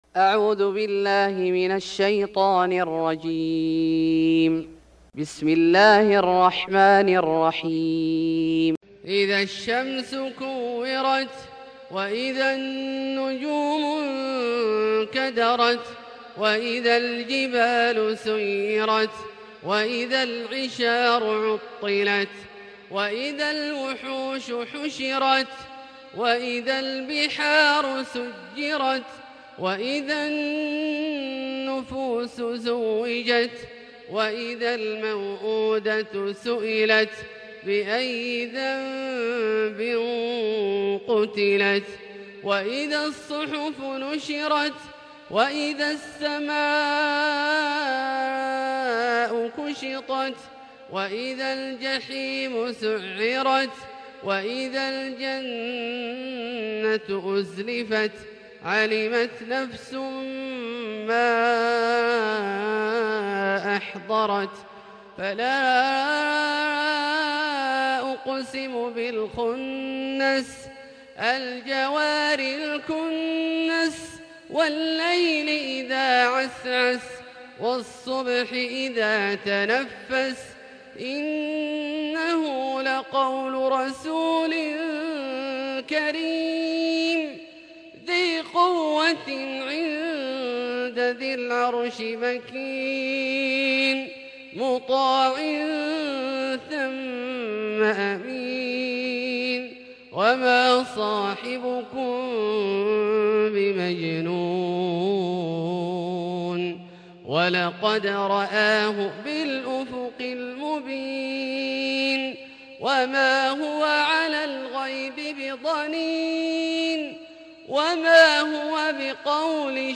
سورة التكوير Surat At-Takwir > مصحف الشيخ عبدالله الجهني من الحرم المكي > المصحف - تلاوات الحرمين